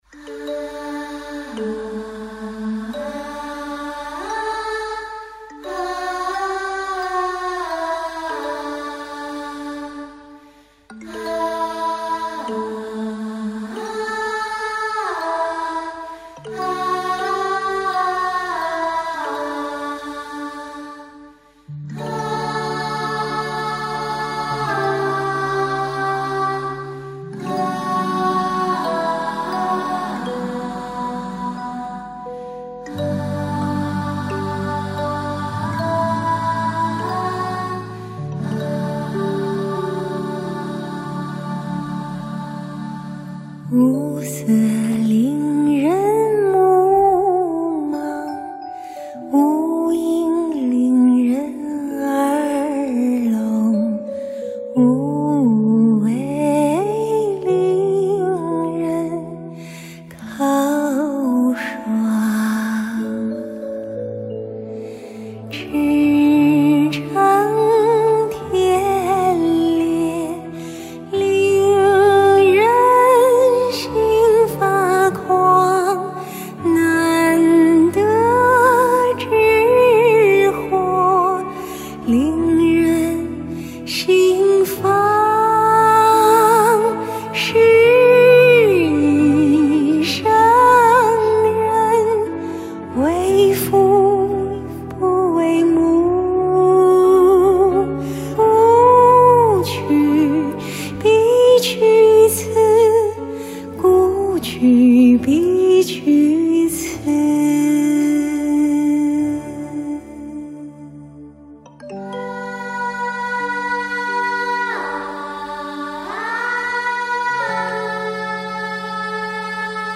古琴
古筝
琵琶
笛子
中阮
女声伴唱组
男声伴唱